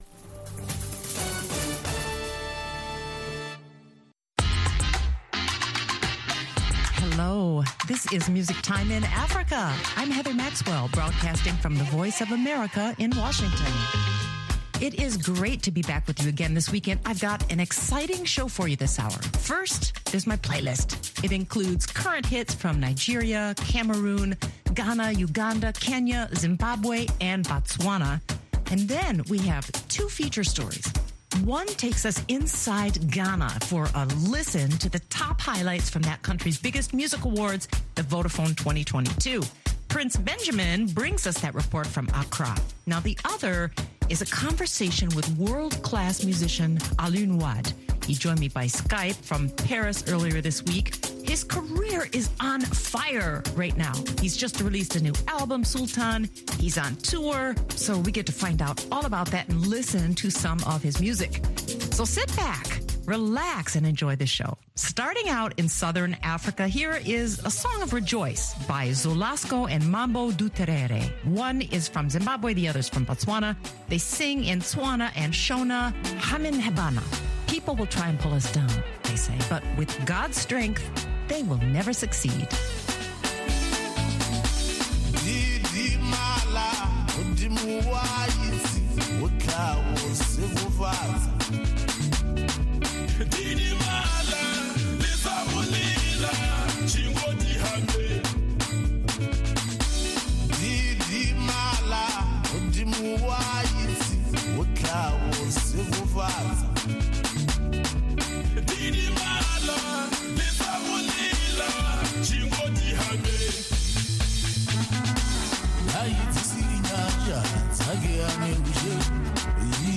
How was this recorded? Take a trip inside Ghana for a listen to the top highlights from that country’s biggest music awards the VODAPHONE 2022.